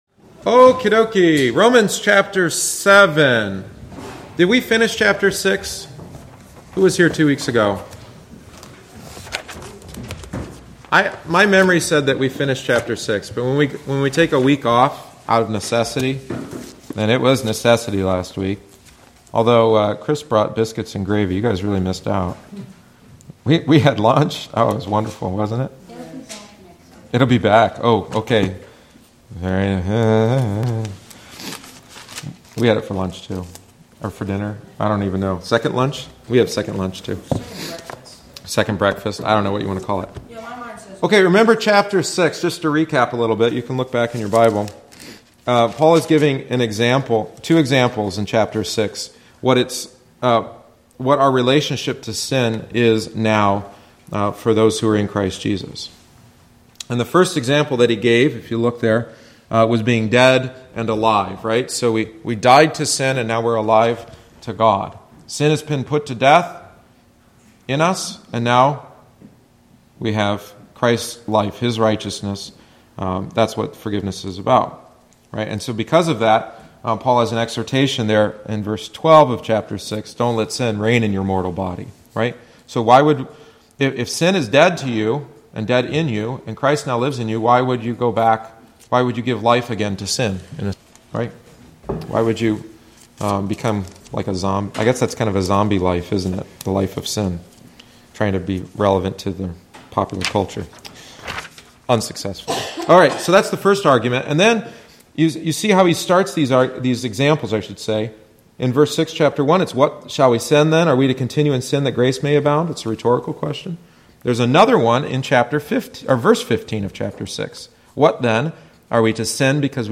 The following is the seventeenth week’s lesson. Paul presents a third way of looking at our relationship with Christ. In Christ we have died to the law.